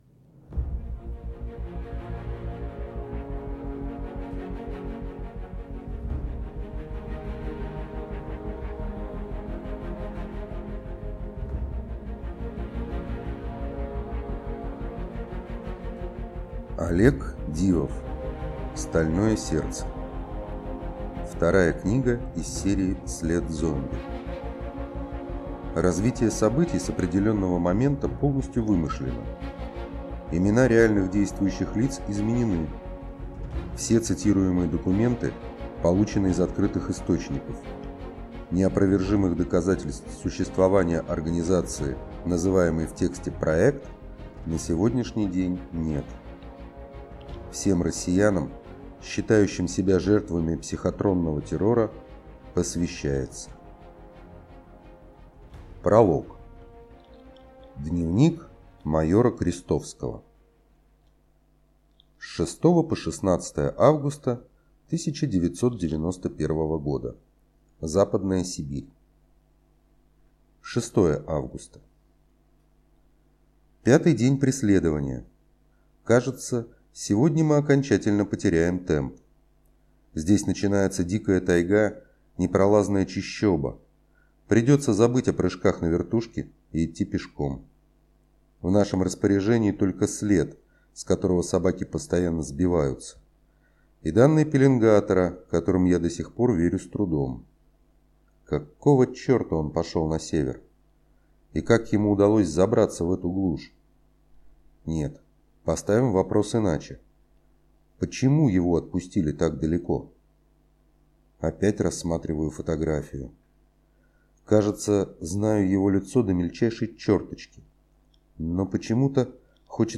Аудиокнига Стальное сердце | Библиотека аудиокниг